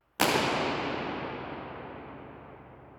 Irs_Ambeo-Ambix_primo-piano-2-trim.wav